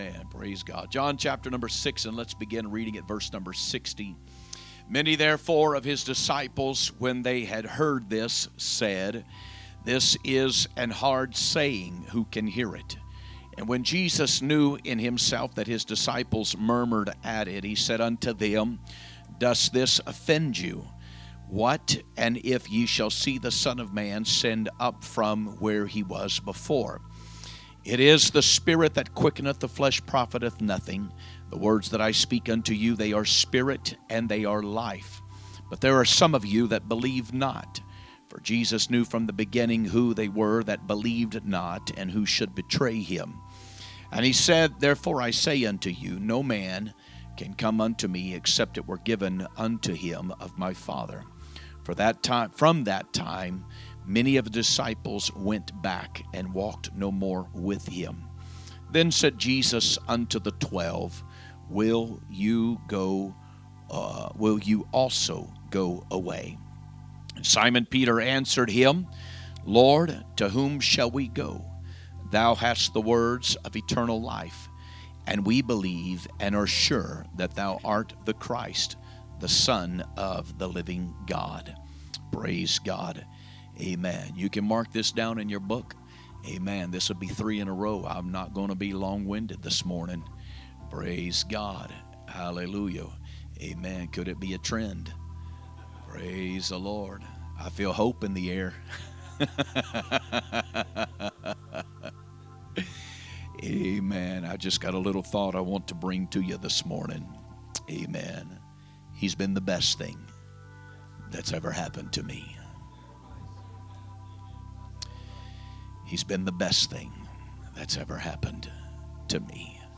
Sunday Morning Message
2025 Sermons